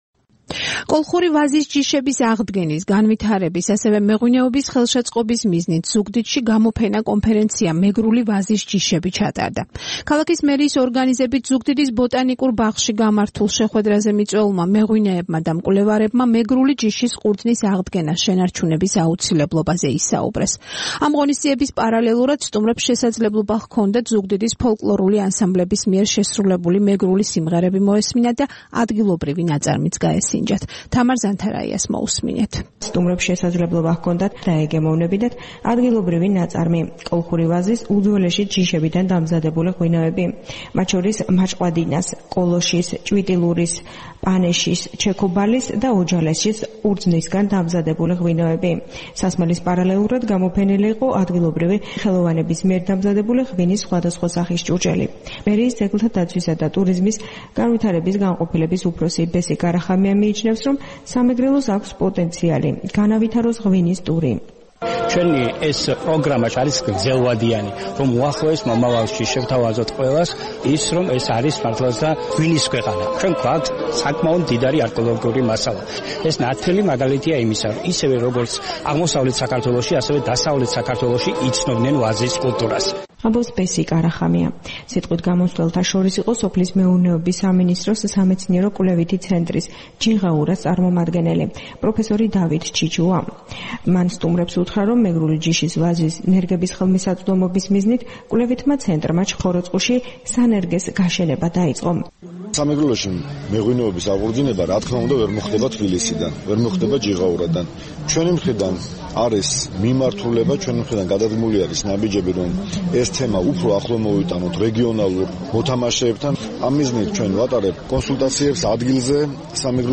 გამოფენა ზუგდიდის ბოტანიკურ ბაღში